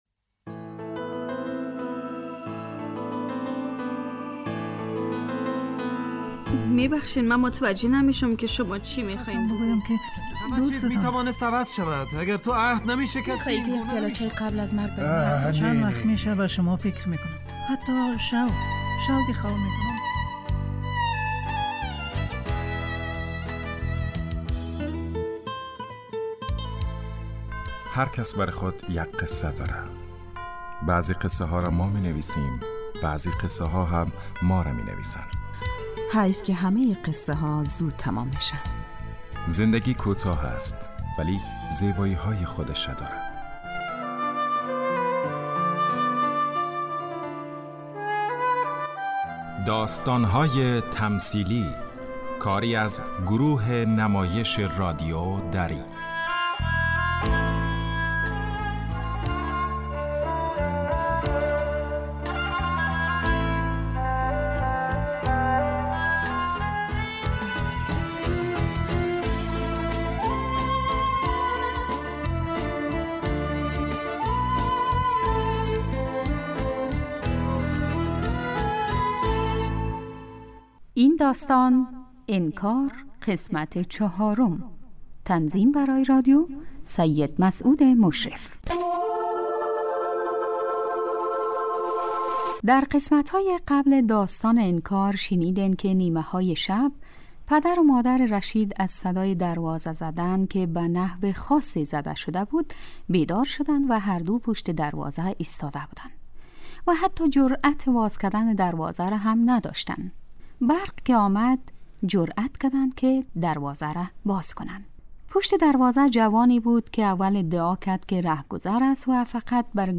داستانهای تمثیلی نمایش 15 دقیقه ای هستند که هر روز ساعت 3:30 عصربه وقت وافغانستان پخش می شود.